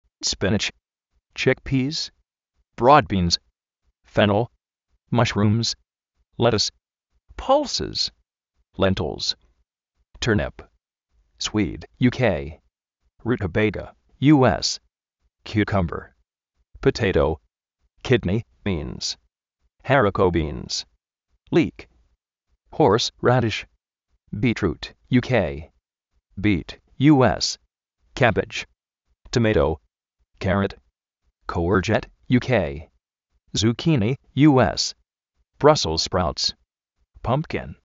spínidch
másh-rúms
kiúcomber
toméitou (US)tomátou (GB)
brásels spráuts